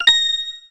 The sound effect used for grabbing, spending or otherwise interacting with Coins in Super Paper Mario.
SPM_Coin_Sound.oga